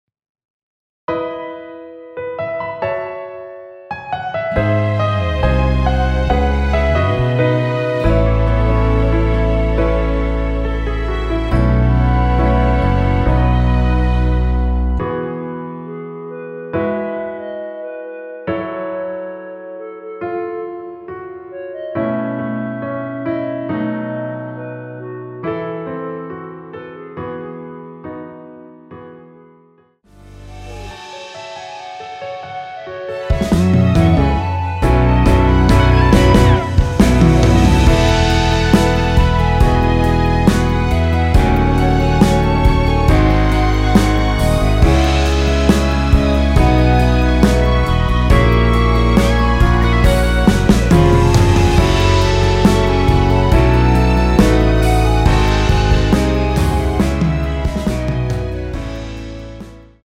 원키에서(+4)올린 멜로디 포함된 MR입니다.(미리듣기 확인)
앞부분30초, 뒷부분30초씩 편집해서 올려 드리고 있습니다.
중간에 음이 끈어지고 다시 나오는 이유는